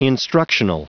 Prononciation audio / Fichier audio de INSTRUCTIONAL en anglais
Prononciation du mot : instructional